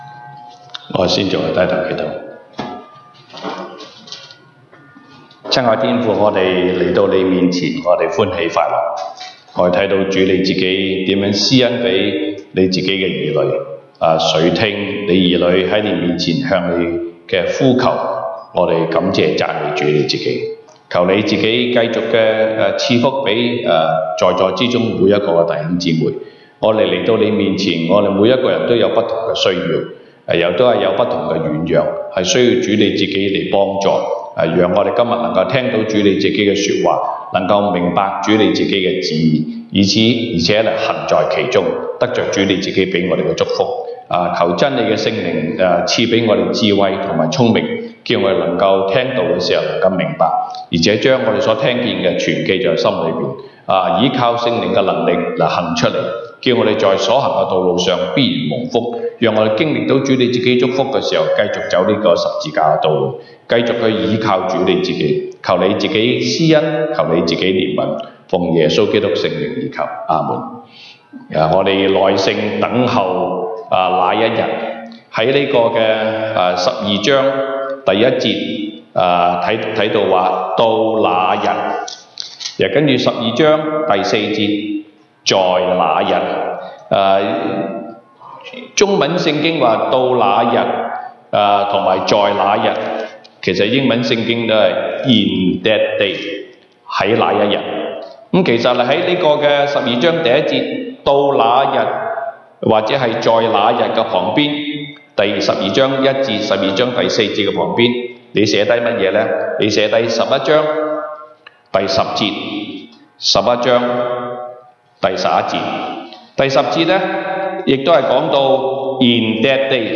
東北堂證道 (粵語) North Side: 等待主再來